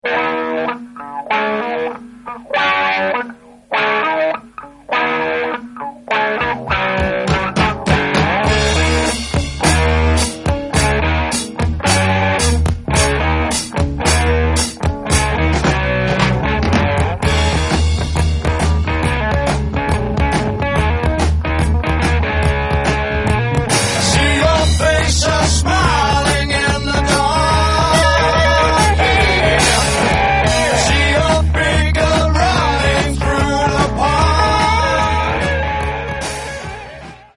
drums
guitar